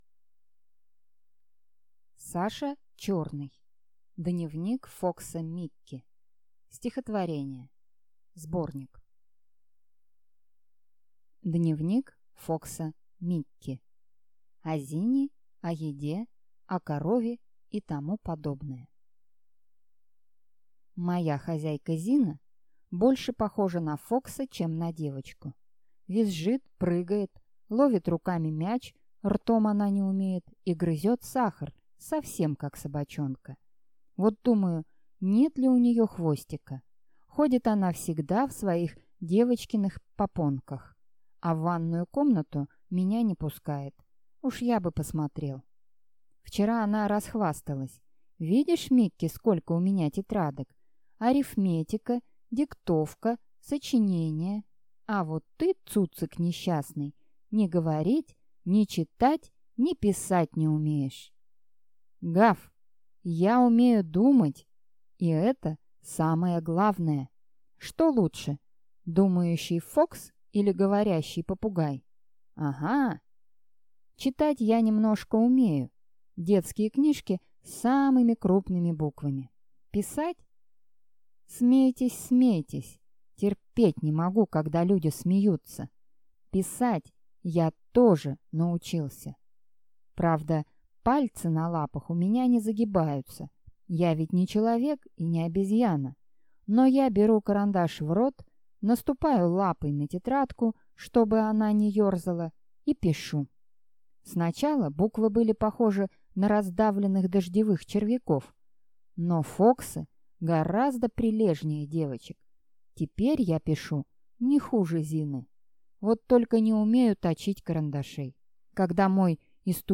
Аудиокнига Дневник фокса Микки. Стихотворения (сборник) | Библиотека аудиокниг